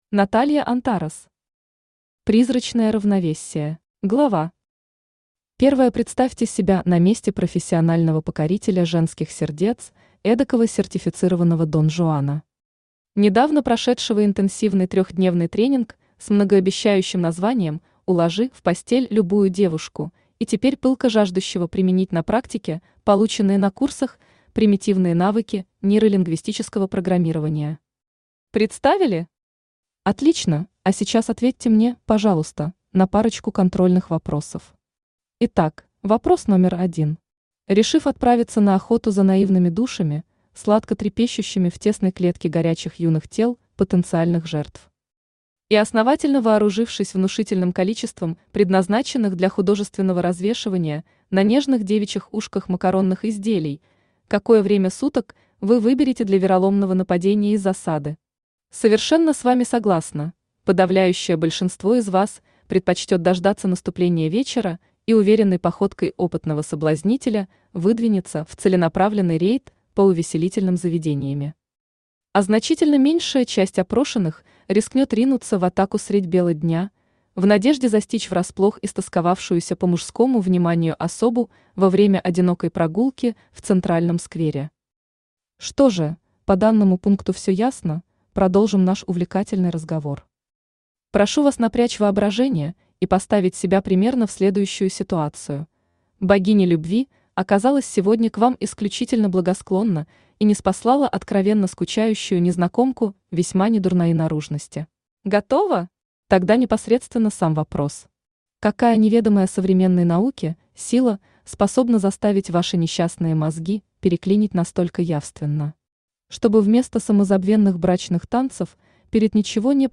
Аудиокнига Призрачное равновесие | Библиотека аудиокниг
Aудиокнига Призрачное равновесие Автор Наталья Антарес Читает аудиокнигу Авточтец ЛитРес.